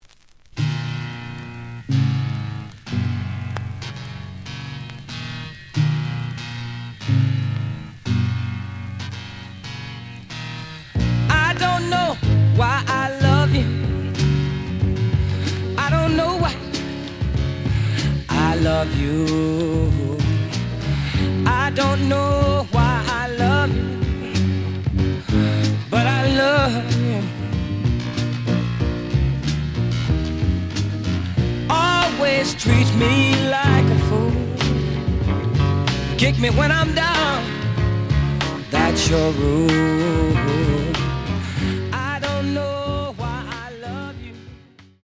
The audio sample is recorded from the actual item.
●Genre: Soul, 60's Soul
B side plays good.